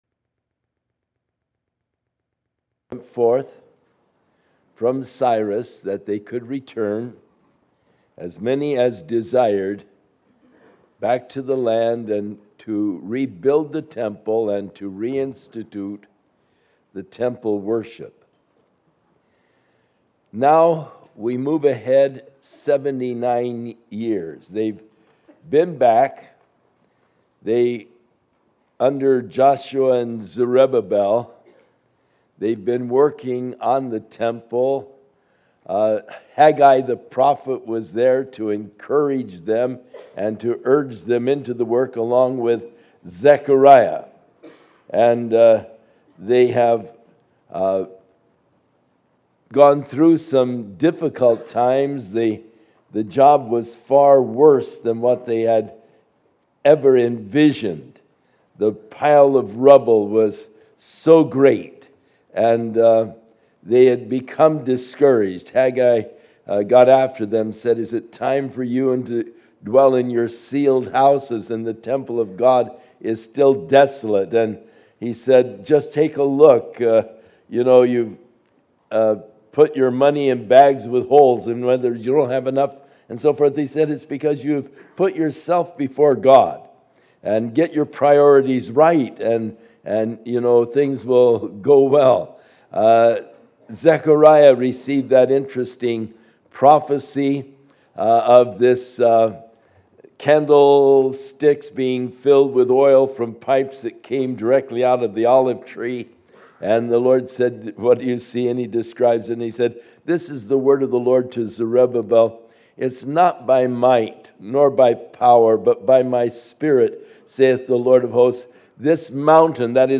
Session 5 Speaker: Chuck Smith Series: 2003 DSPC Conference: Pastors & Leaders Date: January 23, 2003 Home » Sermons » Session 5 Share Facebook Twitter LinkedIn Email Topics: Session 5 « Session 4 Session 6 »